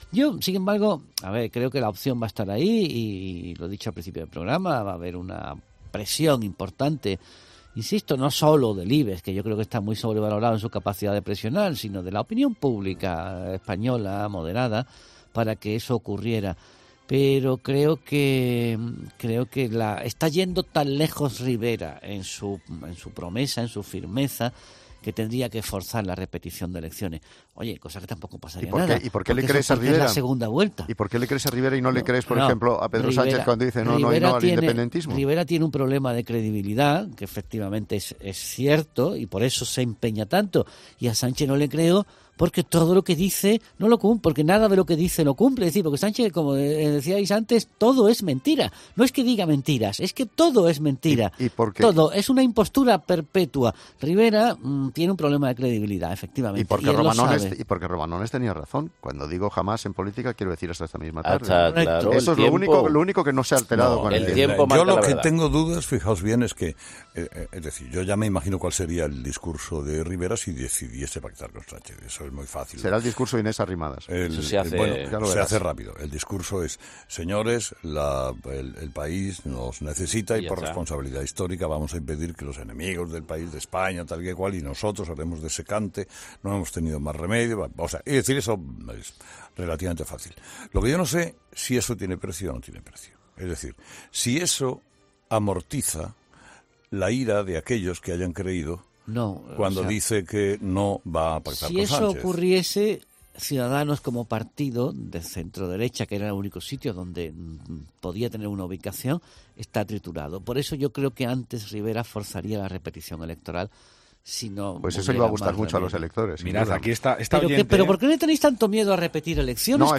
Los tertulianos de Herrera en COPE opinan sobre el posible pacto de Rivera con Sánchez